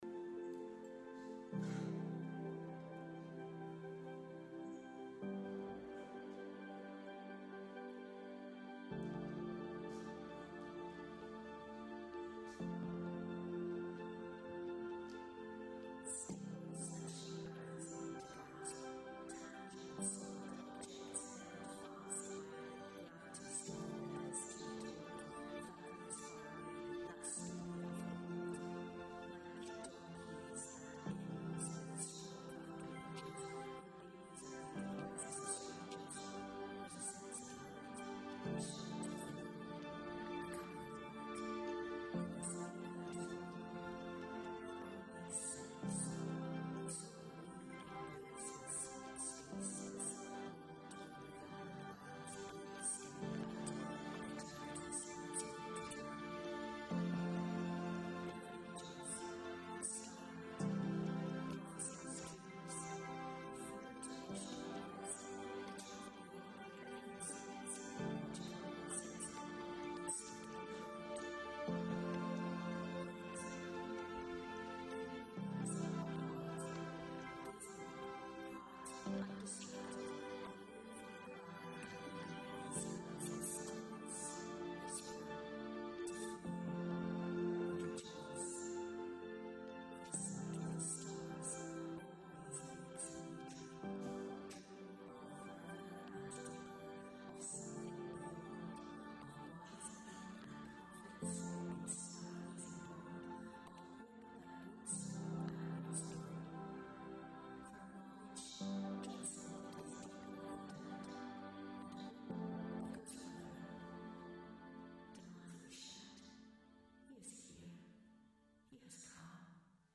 Christmas Eve Service Audio Only Recording